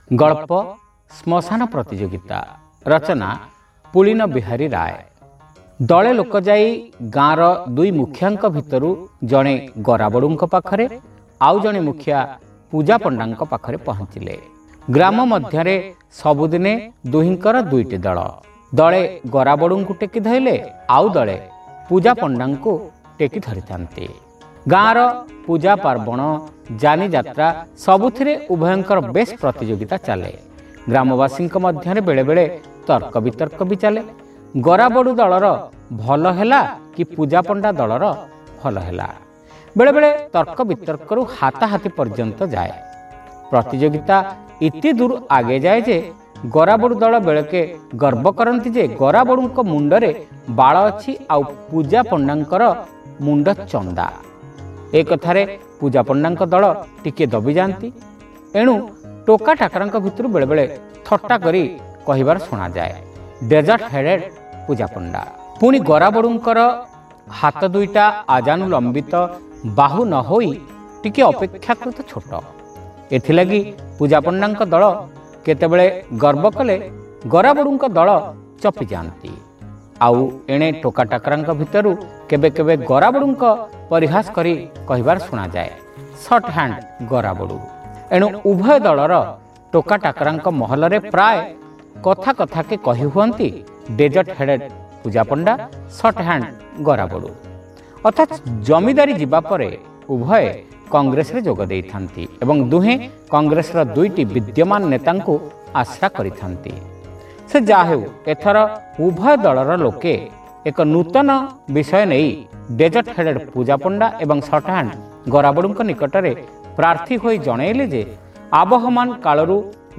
ଶ୍ରାବ୍ୟ ଗଳ୍ପ : ଶ୍ମଶାନ ପ୍ରତିଯୋଗିତା